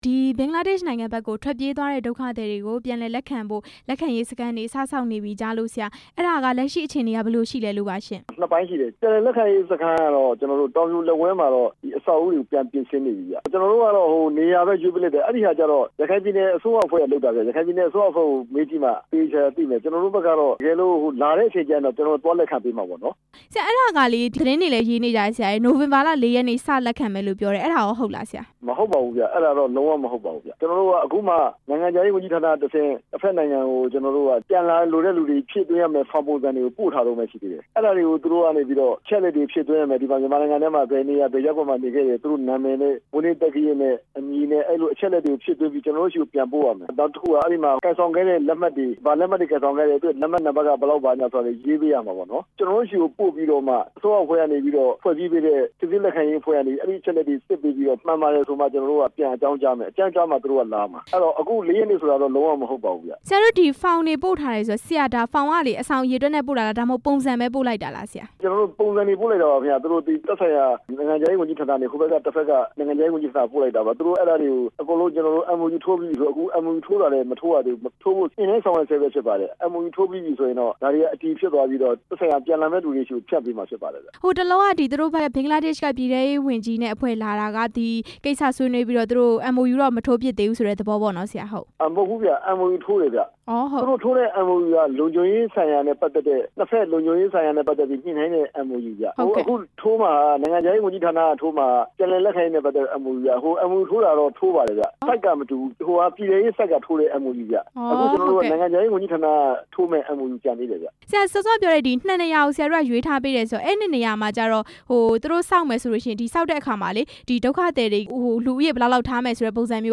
ဒုက္ခသည် ပြန်လည်လက်ခံရေးအကြောင်း မေးမြန်းချက်